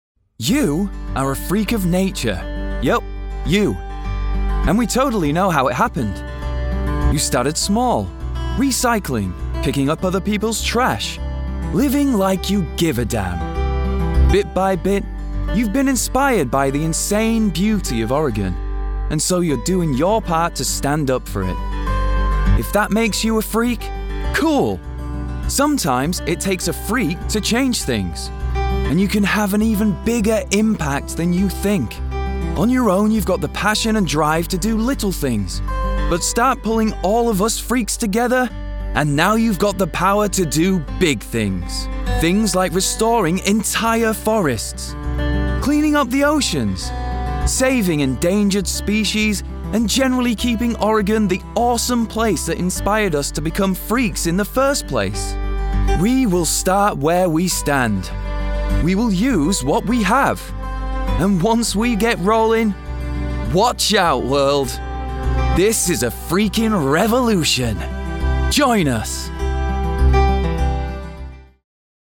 Male
English (British)
Yng Adult (18-29), Adult (30-50)
Video Games
Male Voice Over Talent